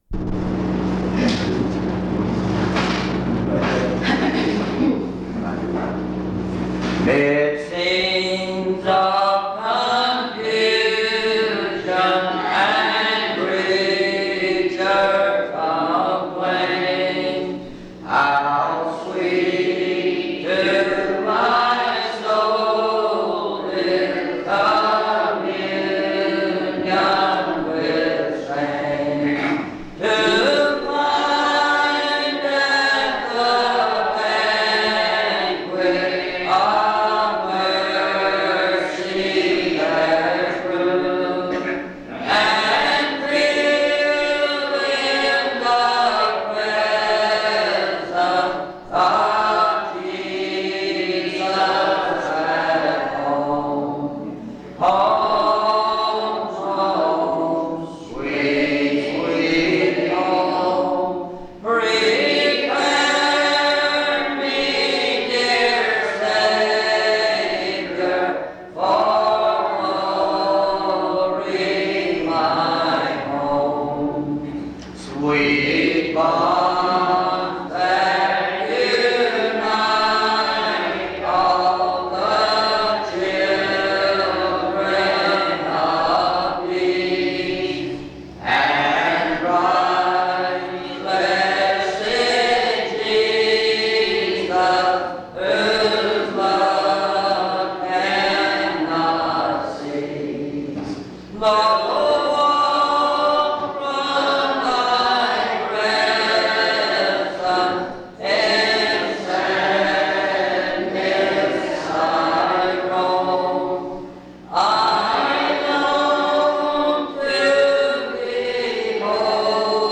En Collection: Reidsville/Lindsey Street Primitive Baptist Church audio recordings Miniatura Título Fecha de subida Visibilidad Acciones PBHLA-ACC.001_019-A-01.wav 2026-02-12 Descargar PBHLA-ACC.001_019-B-01.wav 2026-02-12 Descargar